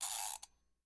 Winding Alarm Clock
Home > Sound Effect > Alarms
Winding_Alarm_Clock.mp3